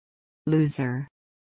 Звуки лузеров
На этой странице собраны забавные звуки лузеров – от провальных фраз до эпичных неудач.